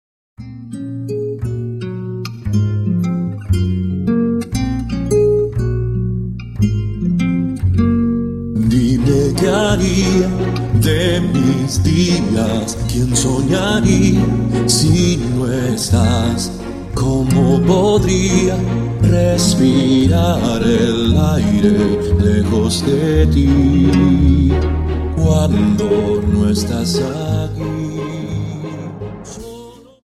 Dance: Viennese Waltz